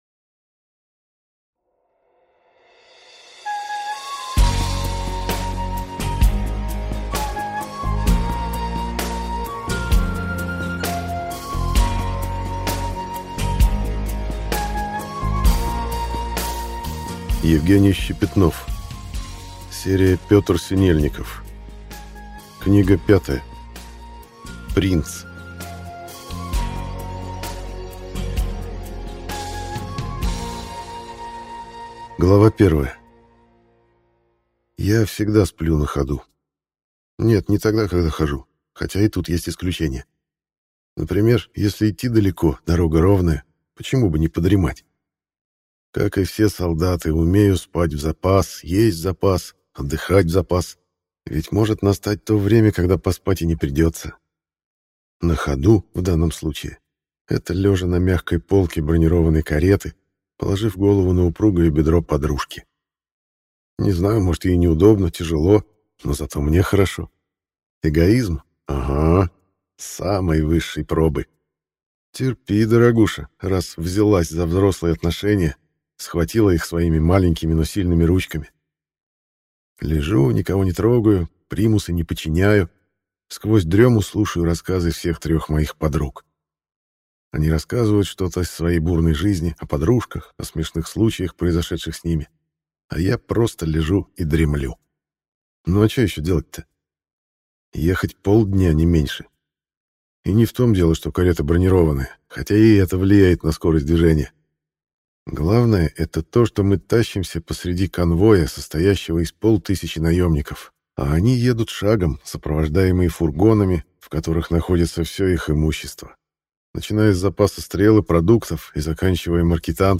Аудиокнига Бандит-5. Принц | Библиотека аудиокниг